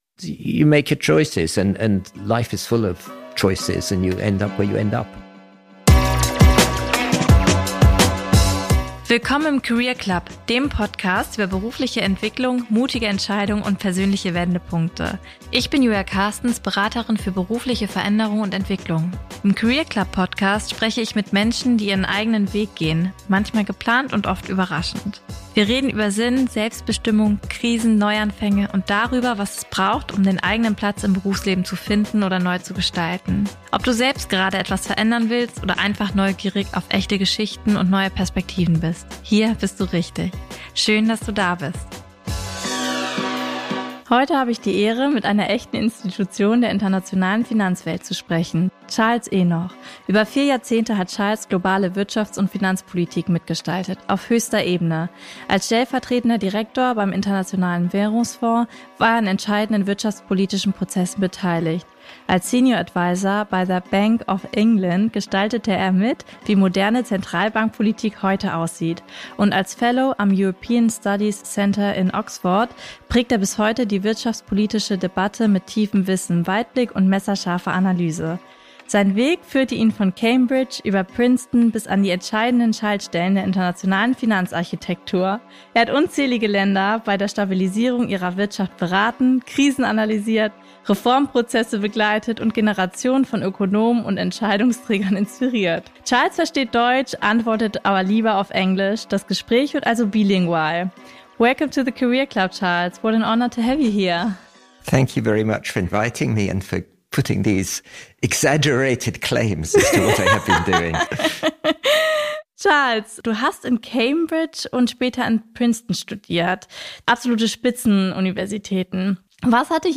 In diesem bilingualen Gespräch (Fragen auf Deutsch, Antworten auf Englisch) sprechen wir über: